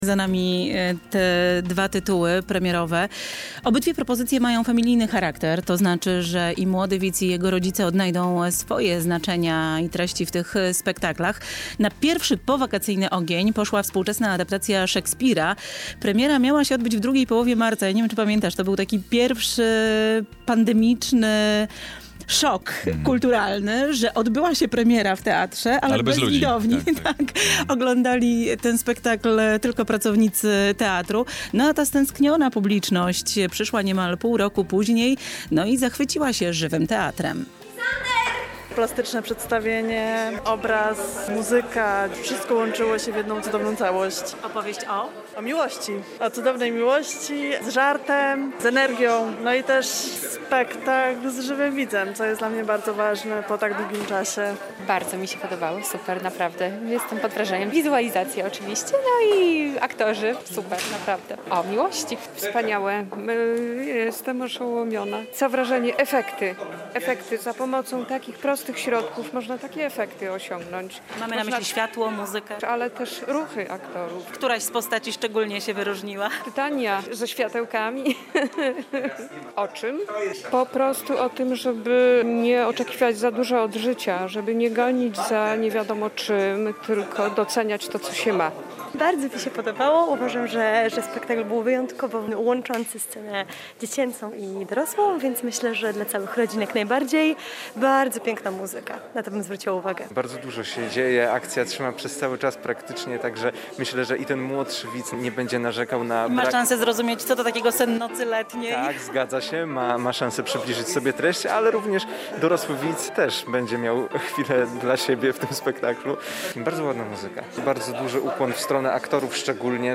"Sen nocy miłości" i "Kaszalot" w BTL-u [recenzja]